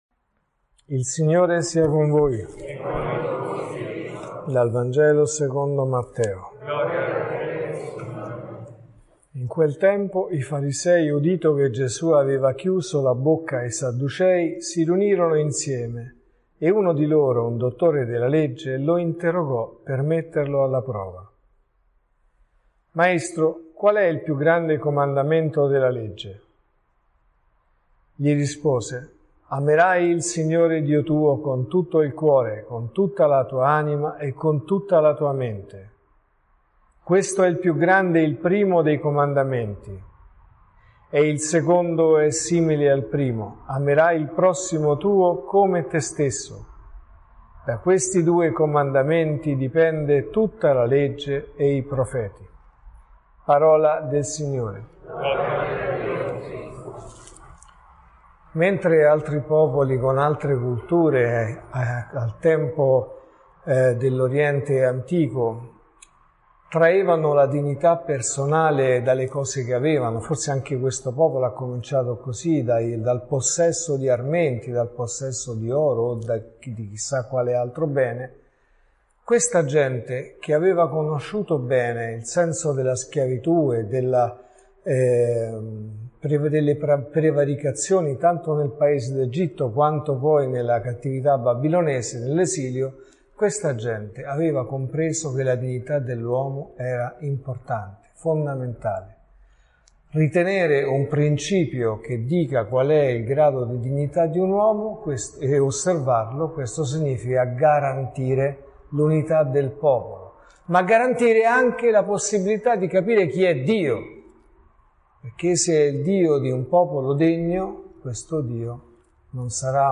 Amerai il Signore tuo Dio, e il tuo prossimo come te stesso.(Messa del mattino e della sera)